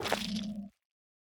Minecraft Version Minecraft Version latest Latest Release | Latest Snapshot latest / assets / minecraft / sounds / block / sculk / place4.ogg Compare With Compare With Latest Release | Latest Snapshot